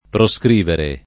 vai all'elenco alfabetico delle voci ingrandisci il carattere 100% rimpicciolisci il carattere stampa invia tramite posta elettronica codividi su Facebook proscrivere [ pro S kr & vere ] v.; proscrivo [ pro S kr & vo ] — coniug. come scrivere